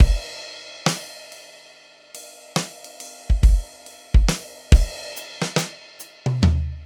Index of /DESN275/loops/Loop Set - Smoke Signal - Live Trip Hop Drums/Loops
SleepTight_70_AcousticDrumFill.wav